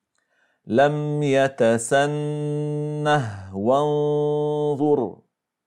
Enligt Ĥafş från ‘Aşim (في روايةِ حفصٍ عن عاصمٍ) läses det med en sukun vid både fortsättnig och stopp, som i: